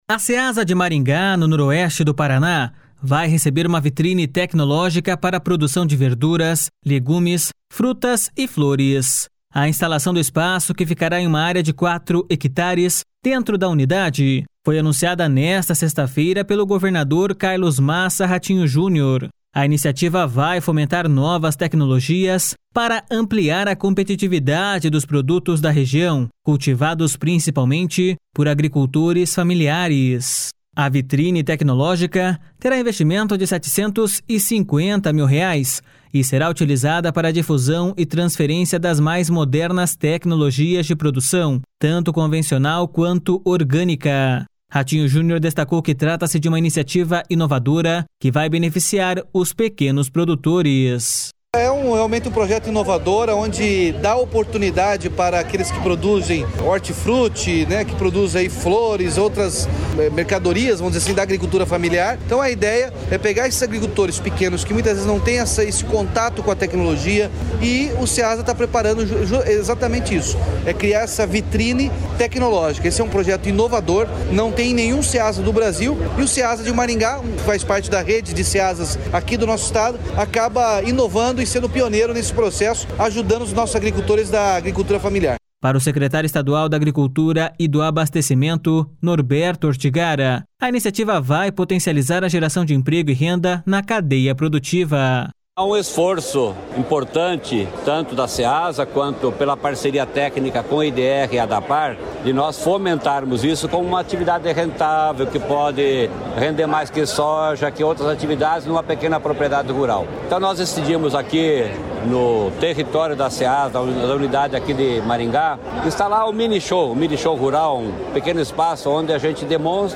A vitrine tecnológica terá investimento de 750 mil reais e será utilizada para difusão e transferência das mais modernas tecnologias de produção, tanto convencional quanto orgânica. Ratinho Junior destacou que trata-se de uma iniciativa inovadora que vai beneficiar os pequenos produtores.// SONORA RATINHO JUNIOR.//
O secretário estadual da Segurança Pública, Hudson Teixeira, explicou que a melhoria na estrutura da corporação se soma a outras iniciativas, como a melhora nos equipamentos e aumento de pessoal.// SONORA HUDSON TEIXEIRA.//